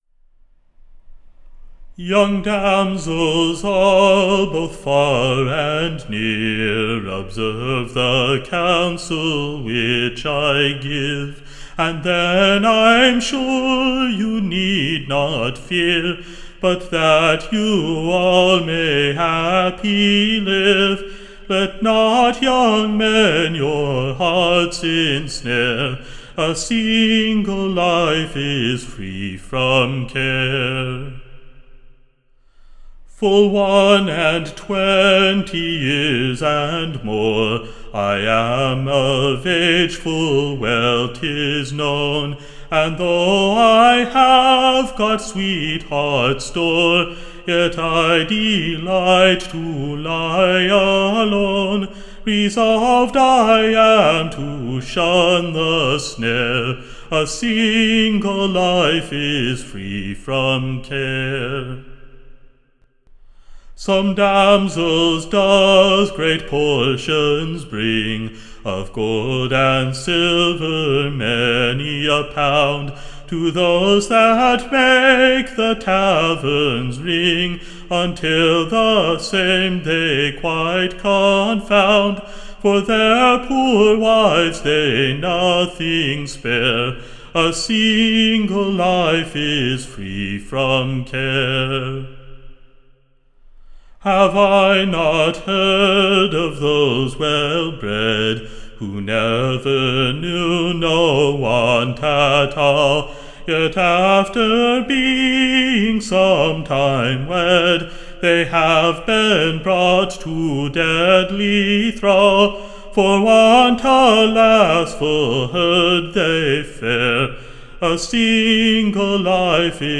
Recording Information Ballad Title The Maidens Counsellor / OR, / A fair VVarning before Marriage.